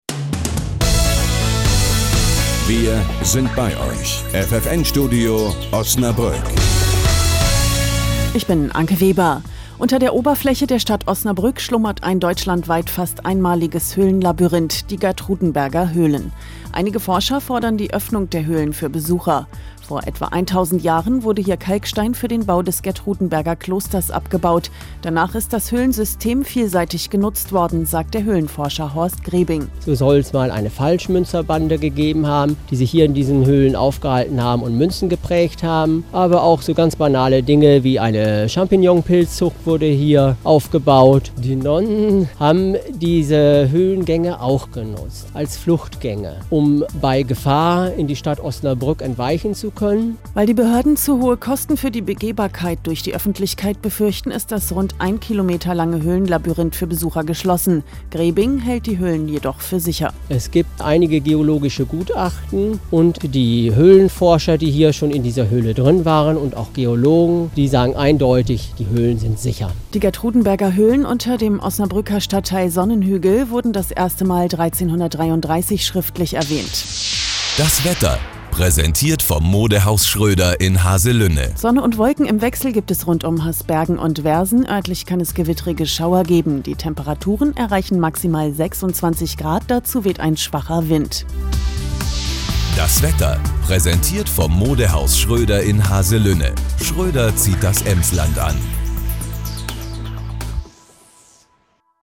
Regionalnachrichten des Regionalstudios Osnabrück von Radio ffn vom 14.07.2009, 16.30 Uhr und 17.30 Uhr